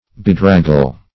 Bedraggle \Be*drag"gle\, v. t. [imp. & p. p. Bedraggled; p.